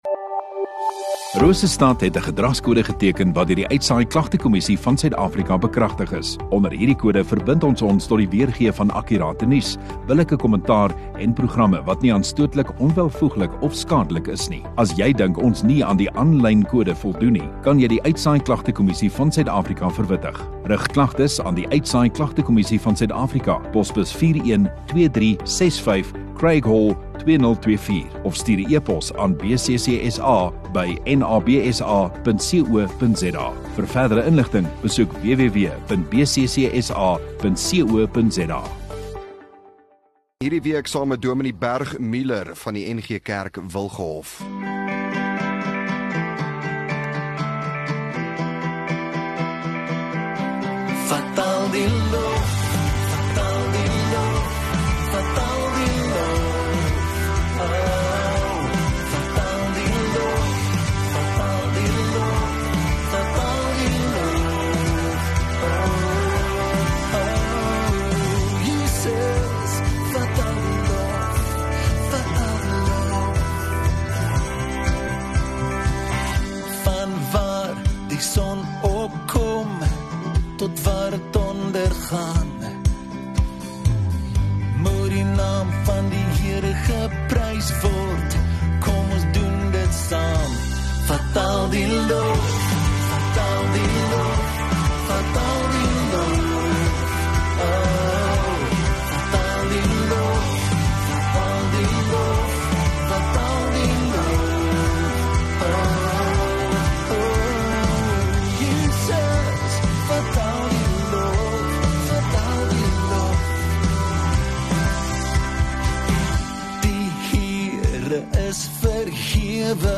17 Jul Donderdag Oggenddiens